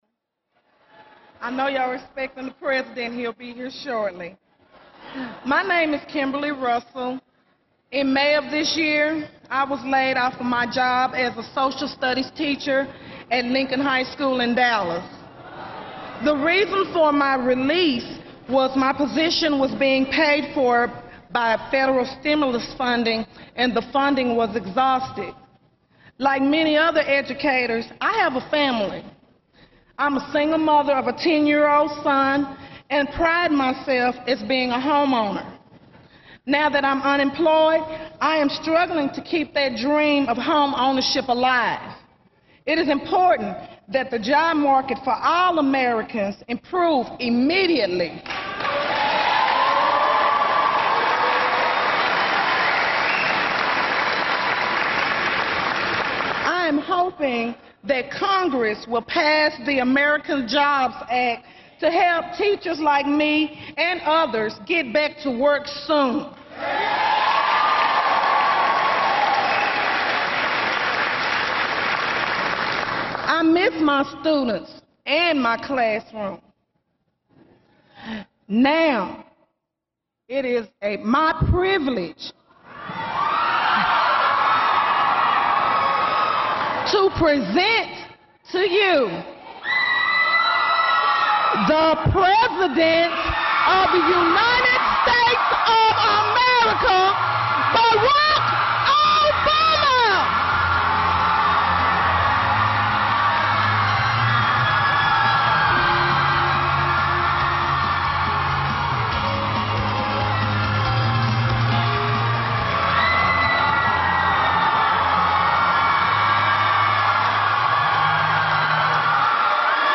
U.S. President Barack Obama speaks about his jobs plan at Eastfield College in Mesquite, Texas